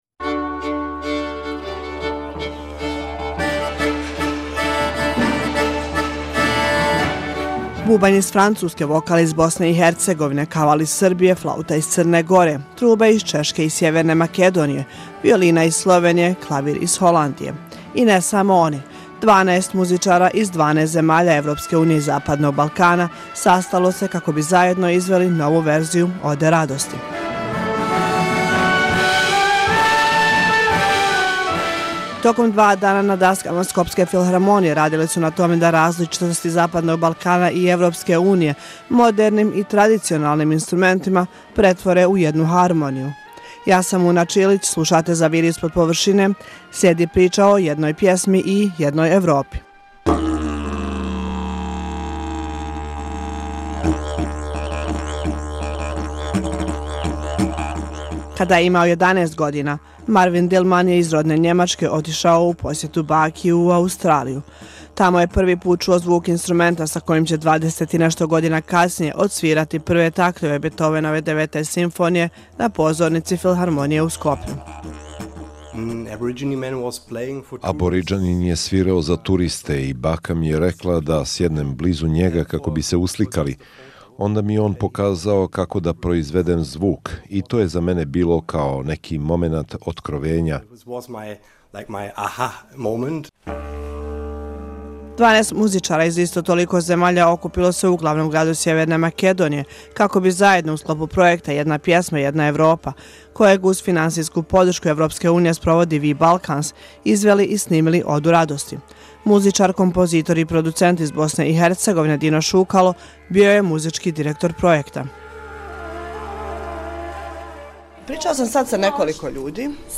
Kako zvuči Oda radosti uz tradicionalne instrumente i sa muzičarima koji su se sreli po prvi put?